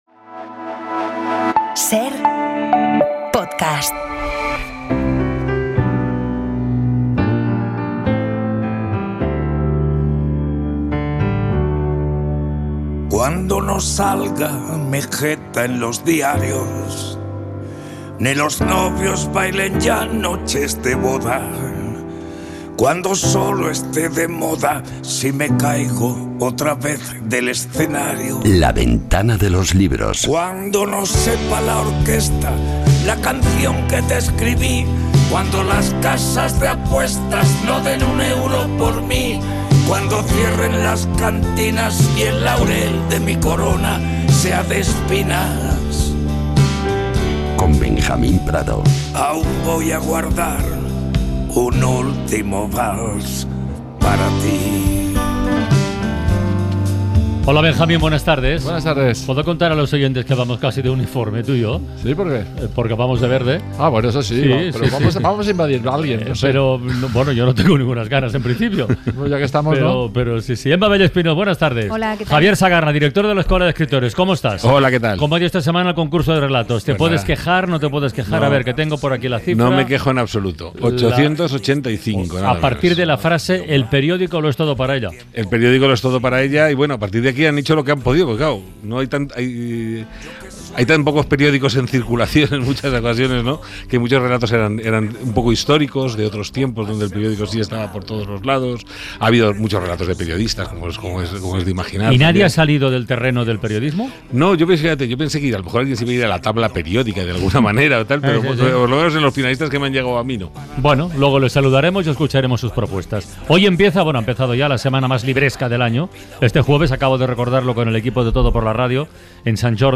La Ventana de los Libros | Entrevista a Elvira Mínguez